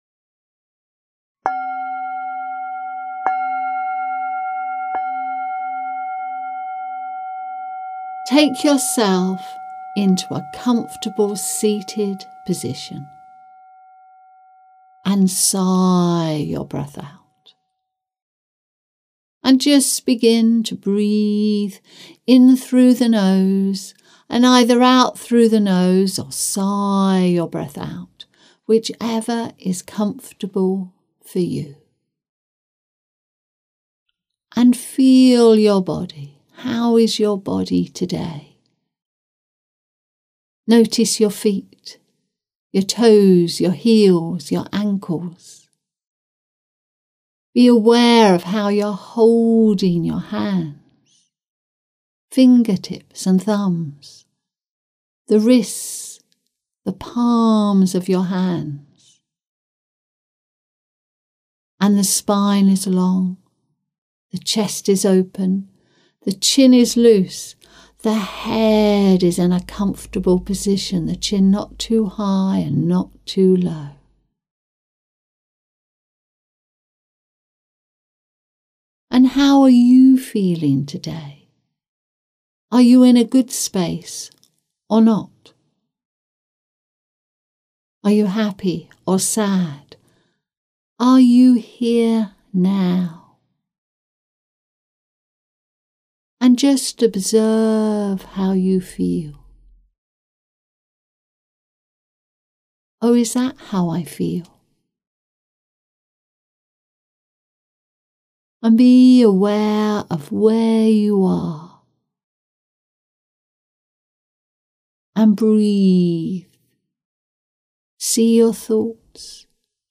bell.mp3